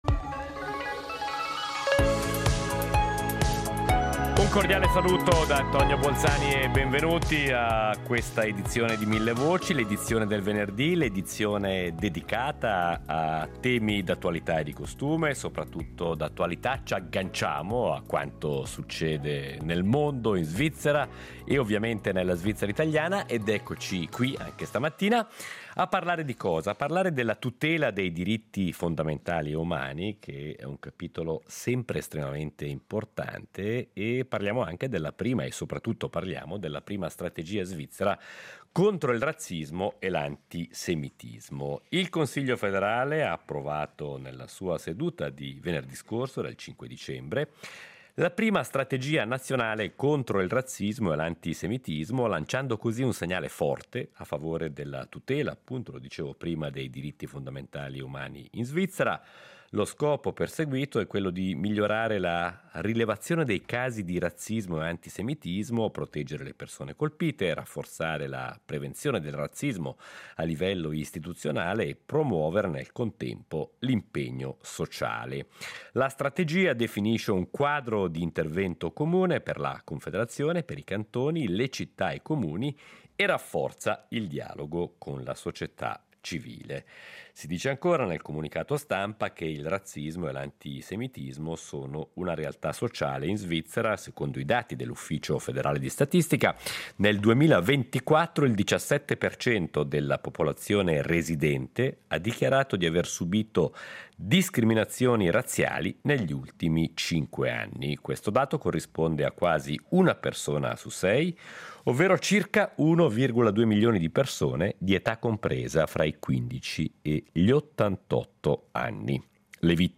Nelle ultime settimana l’attualità ci ha offerto due spunti legati alla nostra società che commentiamo, con un’ esperta, nella puntata odierna.